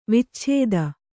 ♪ vicchēda